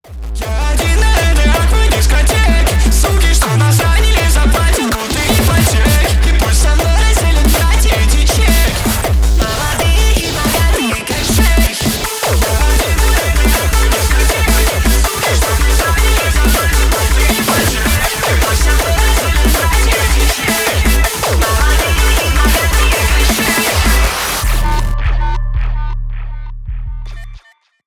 ритмичные # клубные